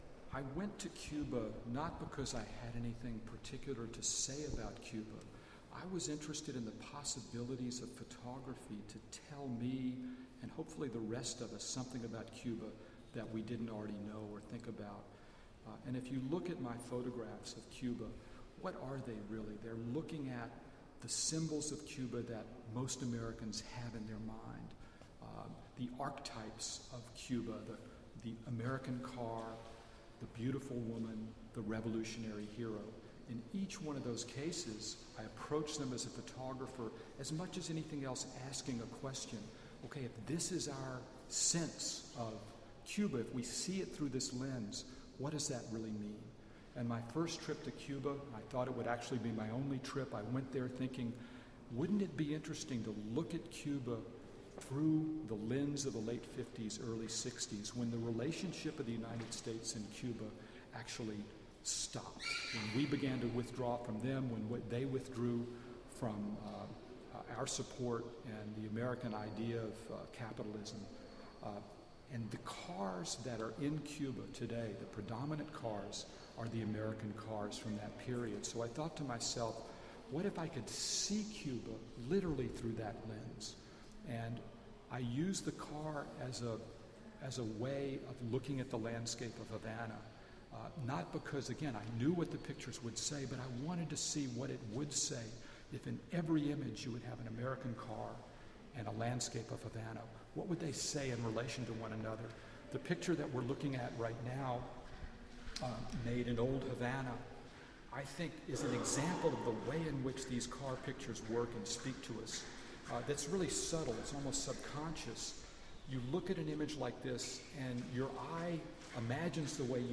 Audio: Interview
Taped in the galleries; please excuse the background noise.